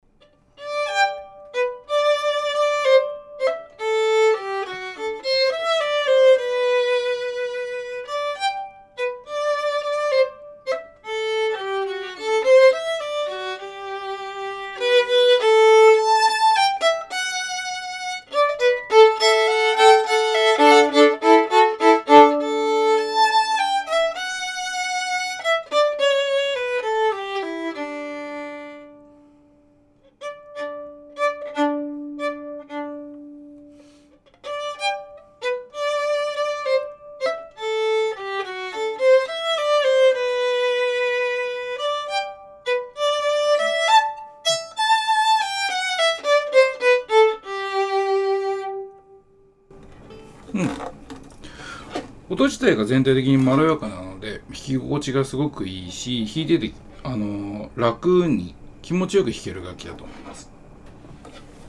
音質：高温域は明るくて・明確で・きれいで・またエネルギッシュです。 より低音域は深くて・強くて・またパワフルです。